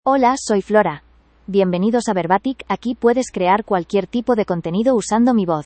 Flora — Female Spanish (Spain) AI Voice | TTS, Voice Cloning & Video | Verbatik AI
Flora is a female AI voice for Spanish (Spain).
Voice sample
Listen to Flora's female Spanish voice.
Flora delivers clear pronunciation with authentic Spain Spanish intonation, making your content sound professionally produced.